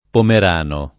[ pomer # no ]